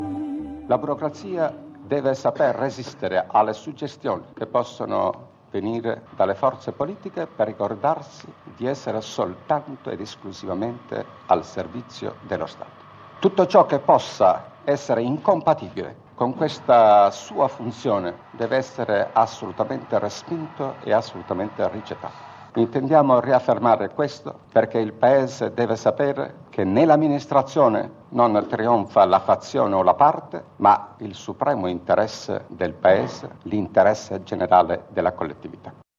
Discorso del Ministro degli Interni Mario Scelba (1954), su funzionari, burocrazia e Stato.
Allocuzione di Mario Scelba ai funzionari pubblici: